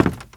STEPS Wood, Creaky, Walk 19.wav